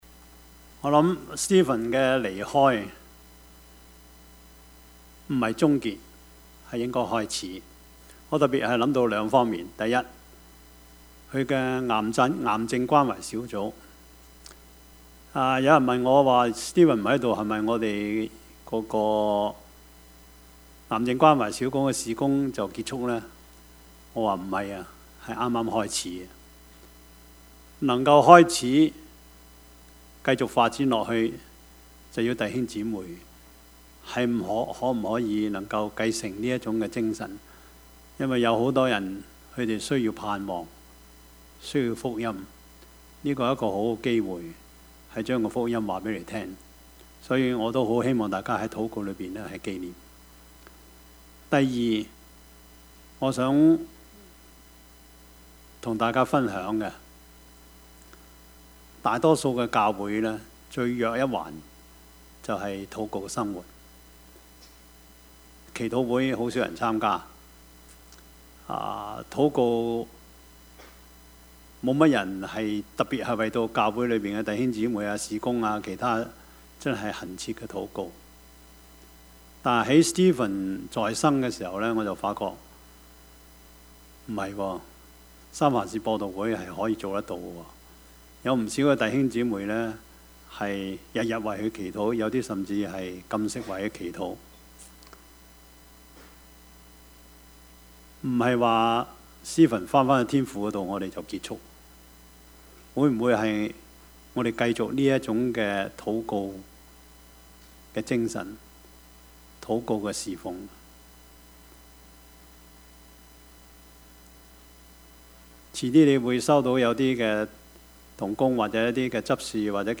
Passage: 傳道書 10:12-20 Service Type: 主日崇拜
Topics: 主日證道 « 英雄與懦夫 波阿斯–仁義禮智信 »